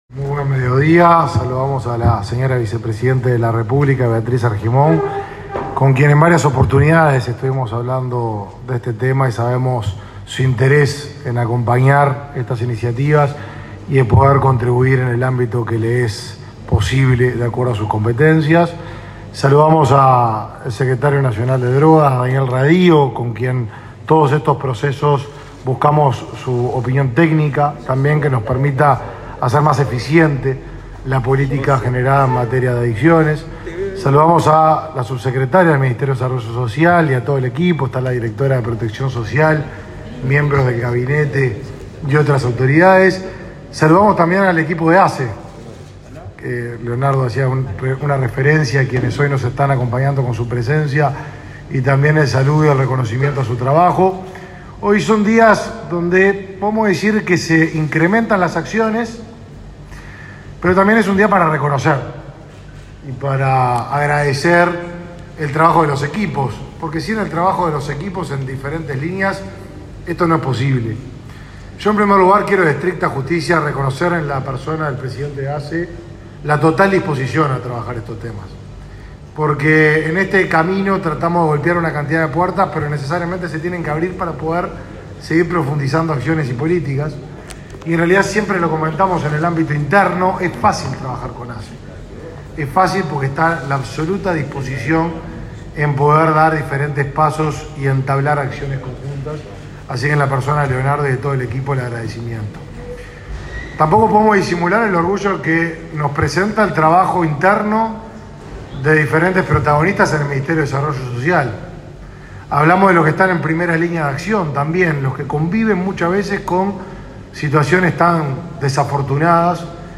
Palabras del ministro Martín Lema
El ministro de Desarrollo Social, Martín Lema, participó este lunes 4, en Montevideo, de la inauguración de dos centros diurnos para personas que